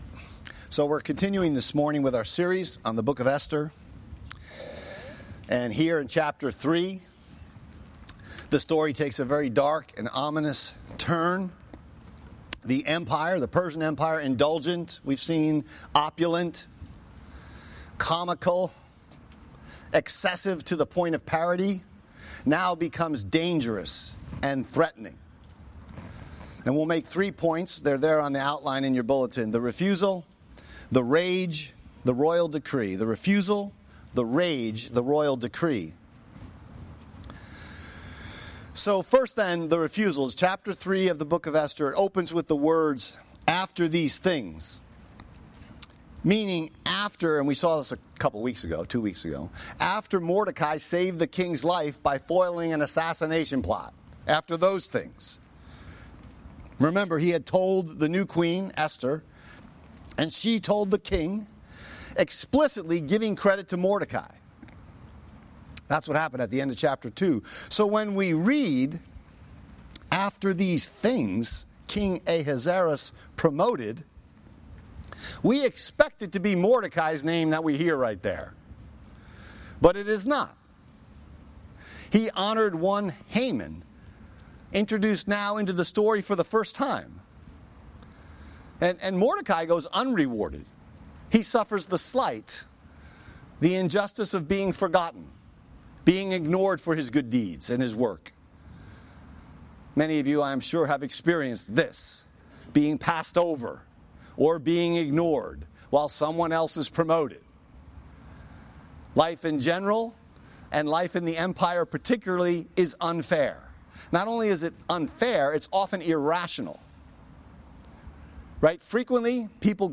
Message text: Esther 3:1-15